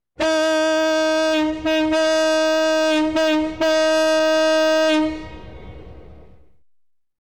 Звуки грузовика, фуры
Мощный гудок грузовой фуры